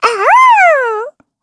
Taily-Vox_Happy4_kr.wav